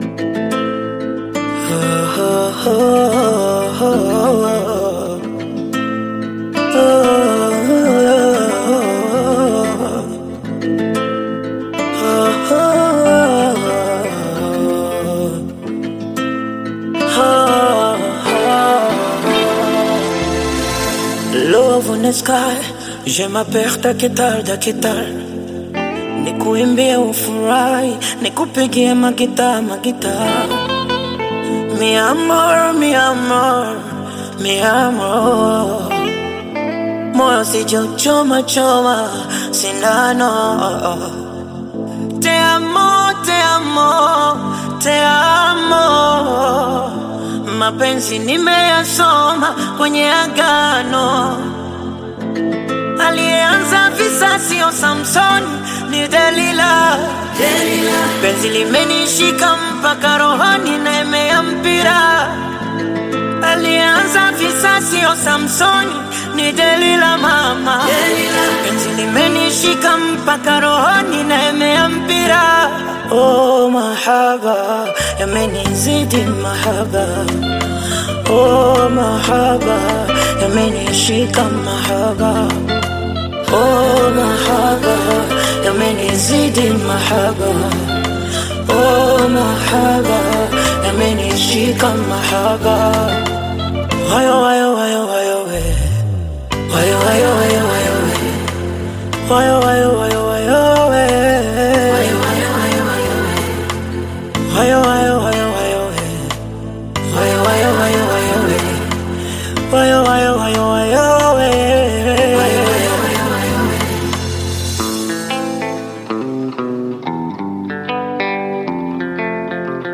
Bongo flava
Acoustic version